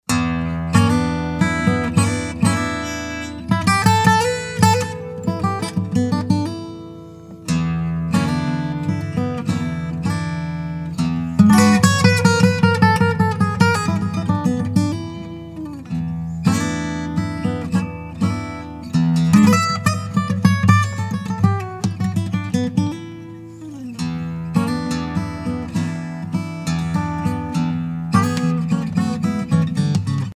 Live in the Studio